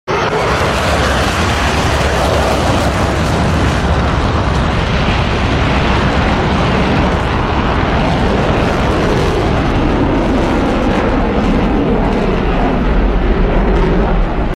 RC F 16 and F 18 With sound effects free download
RC F-16 and F-18 With a little Enhanced Sound fun.